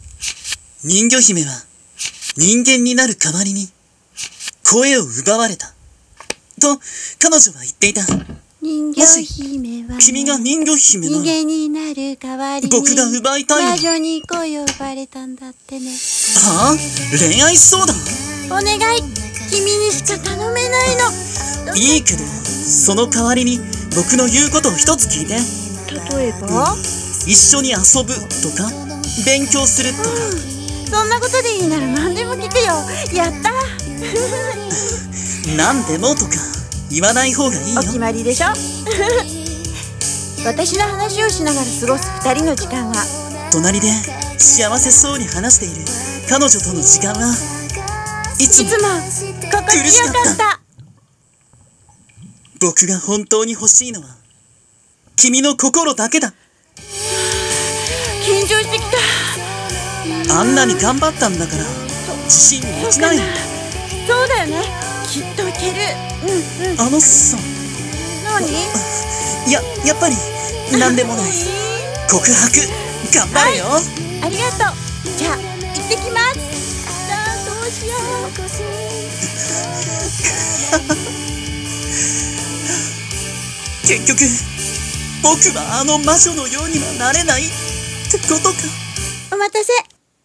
】【二人声劇】群青色のリナリア【声劇台本】 ◇：。